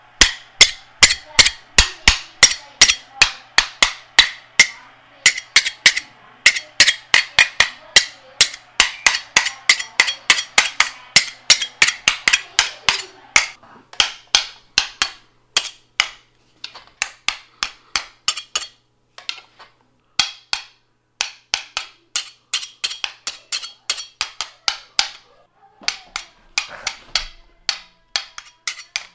刀岩打RAW2
描述：刀子打在石头上